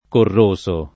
vai all'elenco alfabetico delle voci ingrandisci il carattere 100% rimpicciolisci il carattere stampa invia tramite posta elettronica codividi su Facebook corroso [ korr 1S o o korr 1@ o ] part. pass. di corrodere e agg.